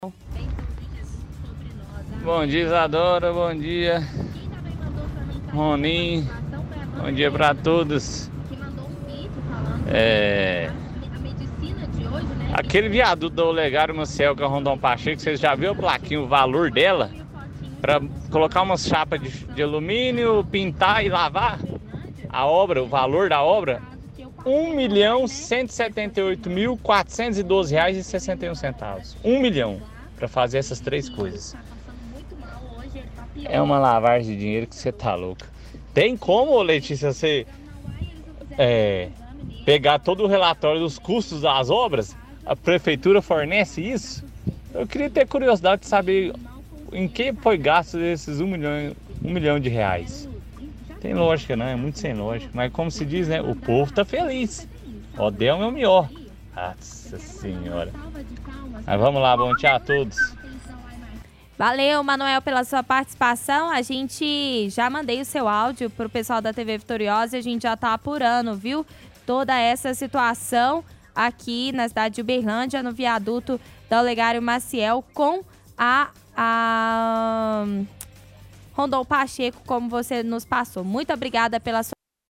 – Ouvinte reclama do valor gasto para obra no viaduto da Olegário Maciel que cruza a avenida Rondon Pacheco.